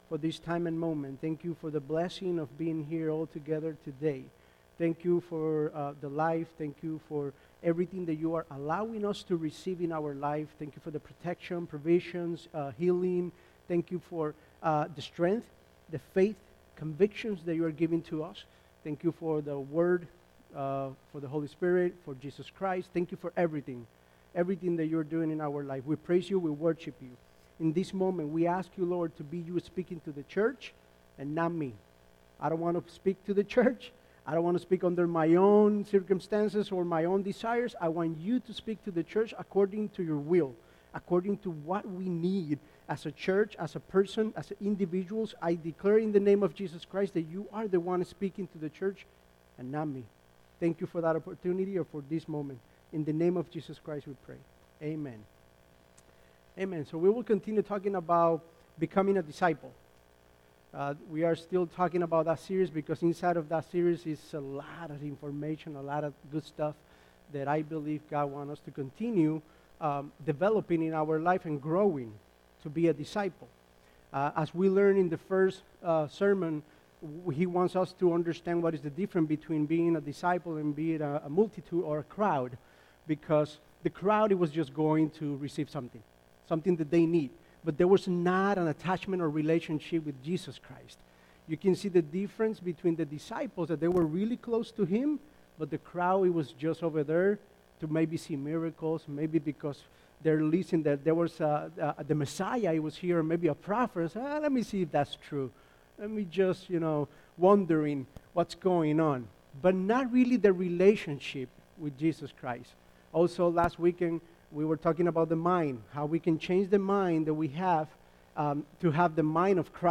Sermons by FLC Elburn